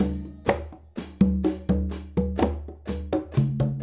Conga and Hi Hat 01.wav